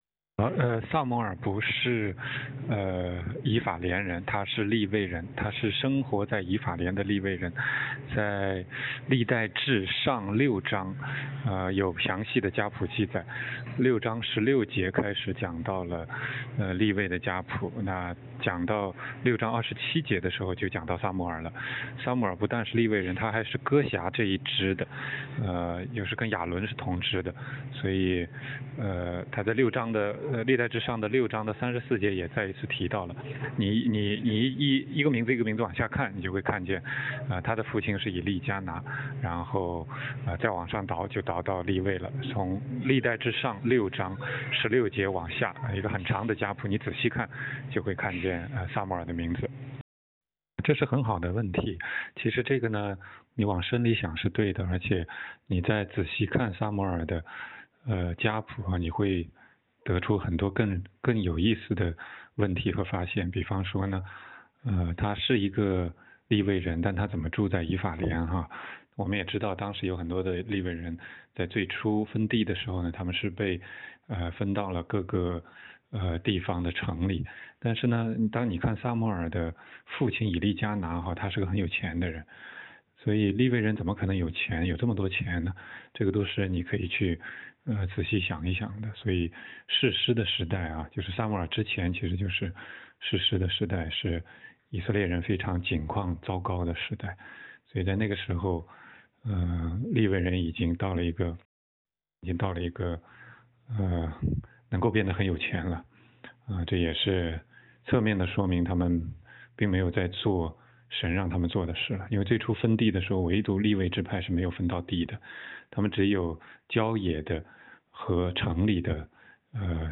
16街讲道录音 - 神拣选利未人做祭司，并且是严格要求的。但是撒母耳是以法莲人，他怎么可以做祭司？有什么寓意吗？